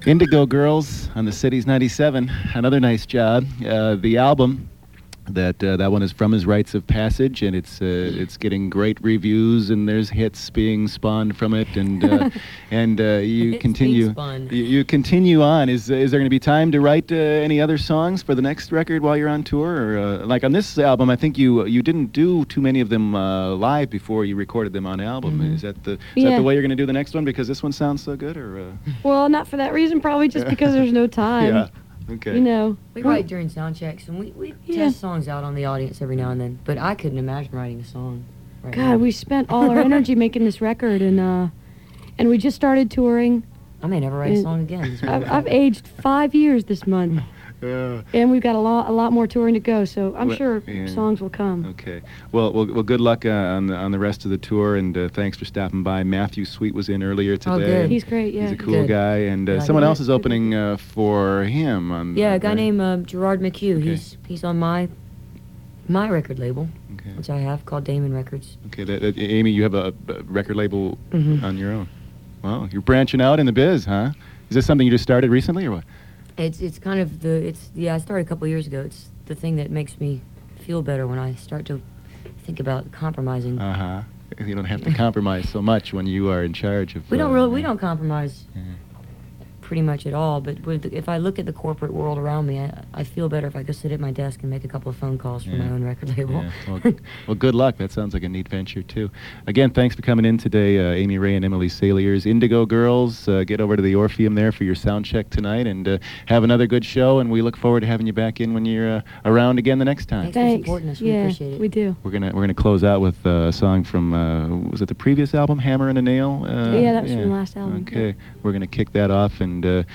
07. interview (2:06)